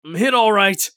eft_usec_wound8.mp3